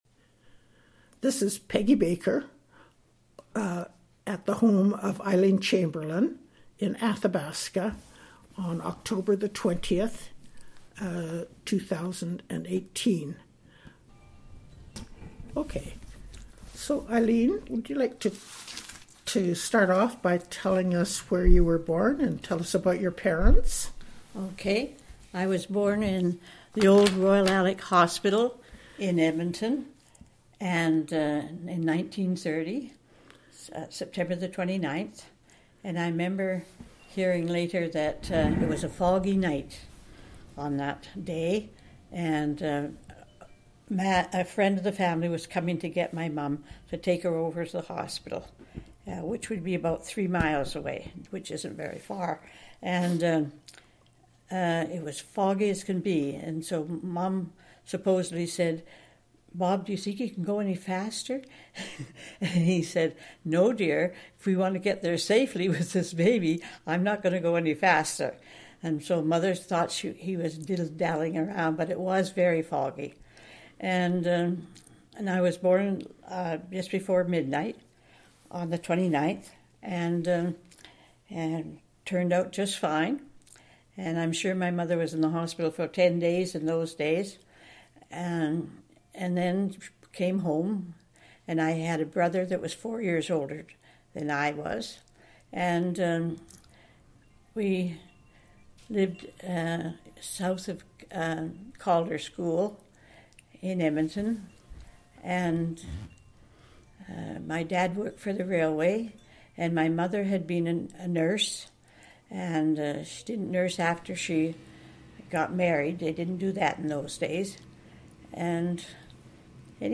Audio interview, transcript of audio interview and obituary,